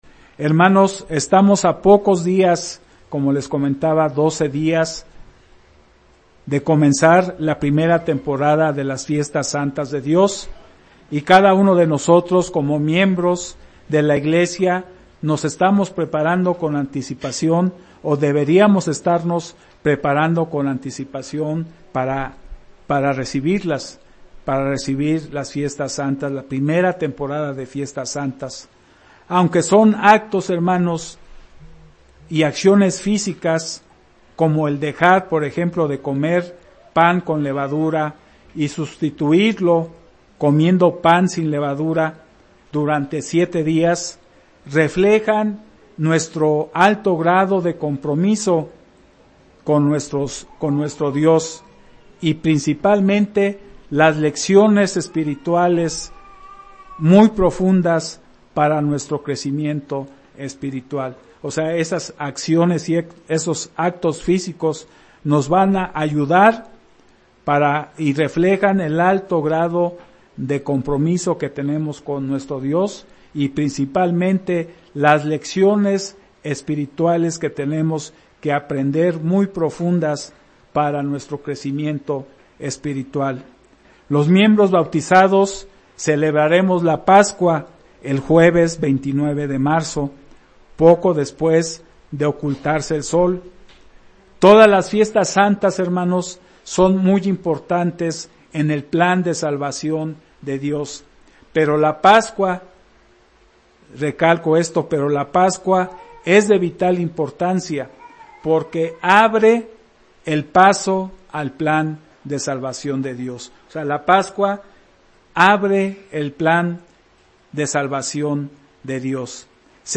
El perdón de todos y cada uno de nuestros pecados, tuvo un precio que habría de pagarse para que no sufriéramos la muerte eterna. Valoremos la preciosa sangre de Cristo, que murió para darnos vida. Mensaje entregado el 17 de marzo de 2018.